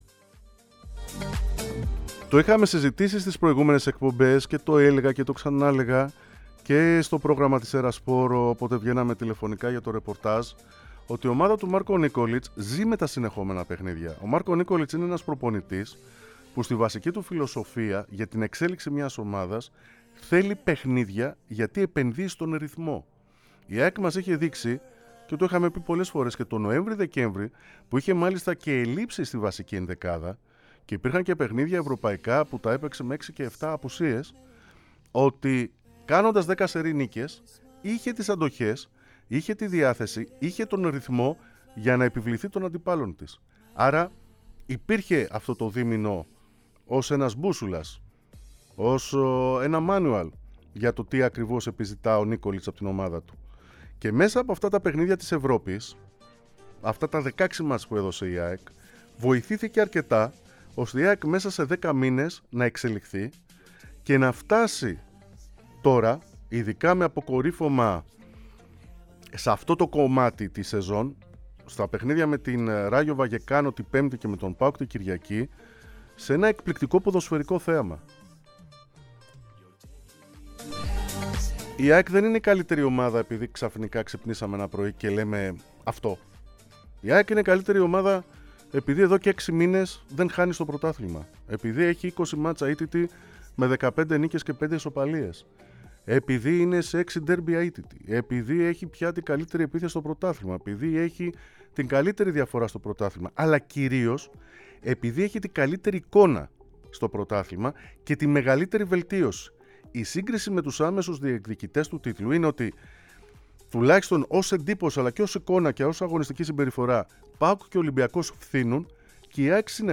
μίλησε στην εκπομπή "Σύστημα 3-5-2"